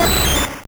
Cri d'Amonistar dans Pokémon Or et Argent.